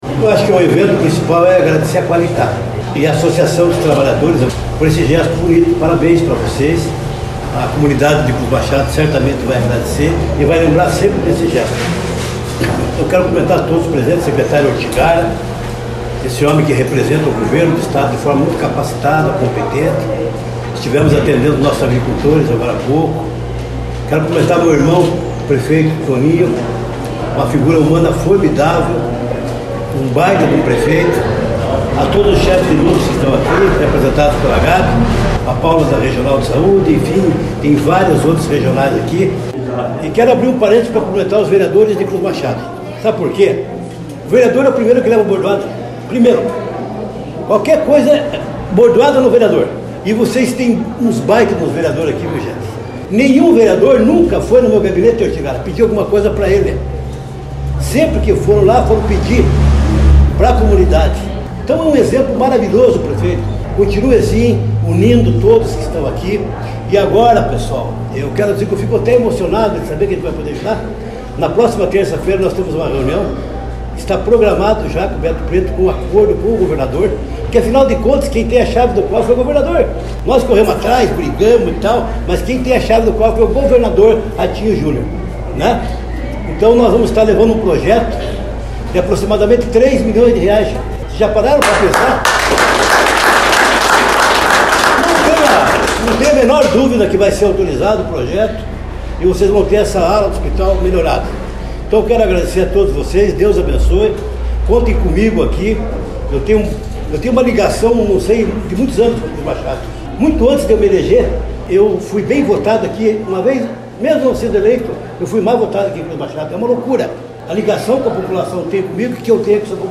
Acompanhe o áudio do Deputado Hussein Bakri abaixo: